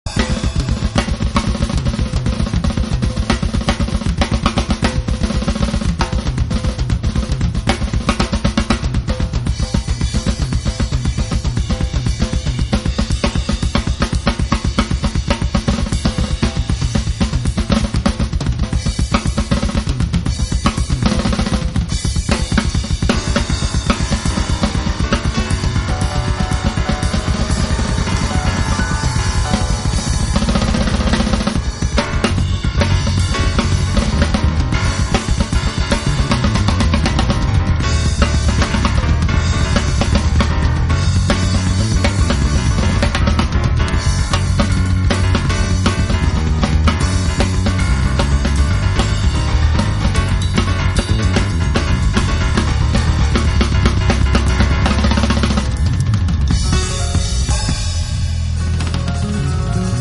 drums, synthesizers, piano
bass
electric guitar